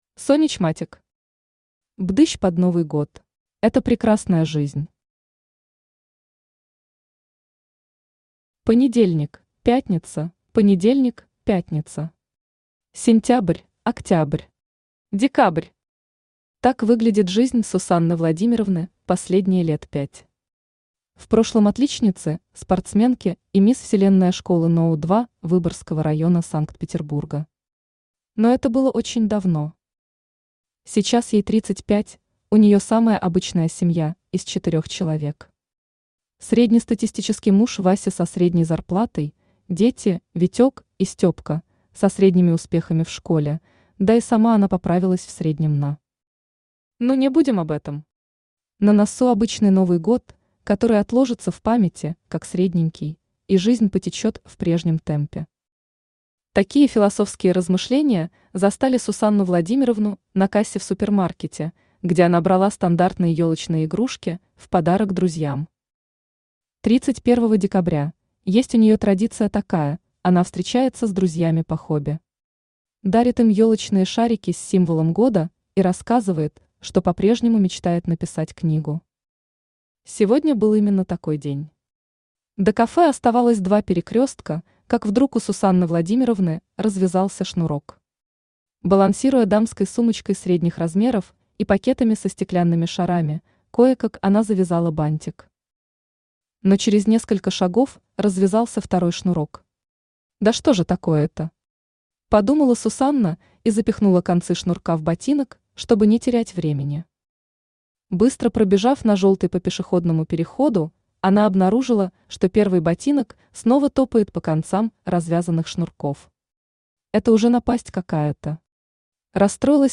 Аудиокнига Пдыщ под Новый год | Библиотека аудиокниг
Aудиокнига Пдыщ под Новый год Автор Сонич Матик Читает аудиокнигу Авточтец ЛитРес.